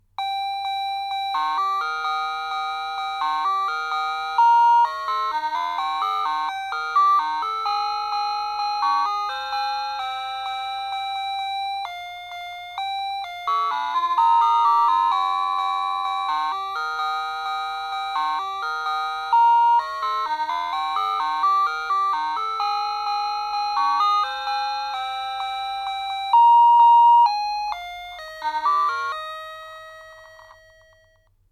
I think it sounded better back then…
(This is it with NEW batteries in!)